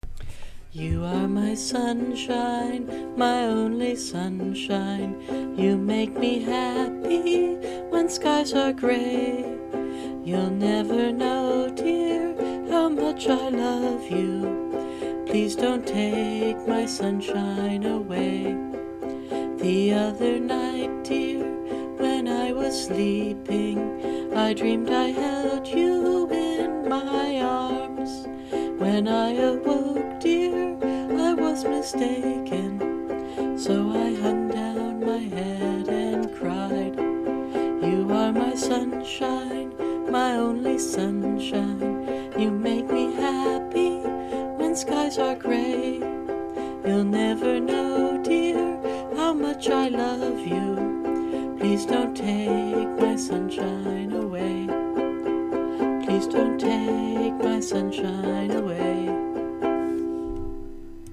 You may notice that my voice is getting a little rough.